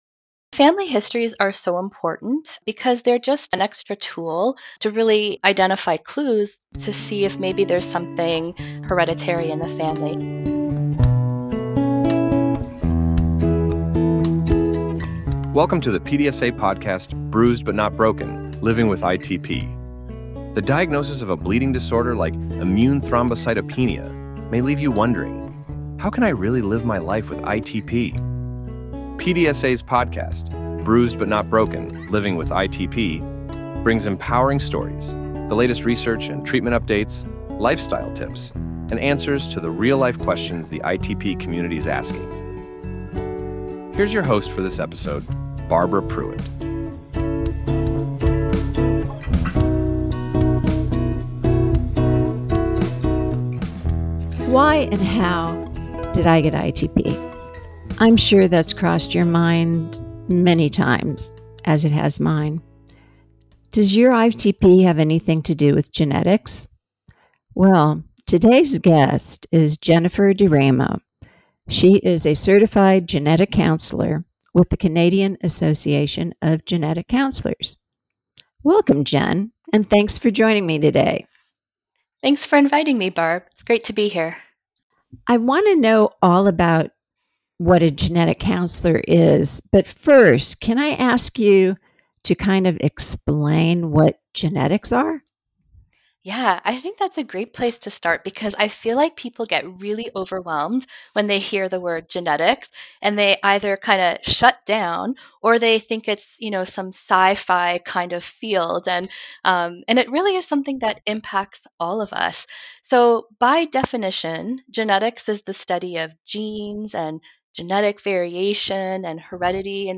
Don’t miss out on this enlightening conversation that could unlock new perspectives on living with ITP.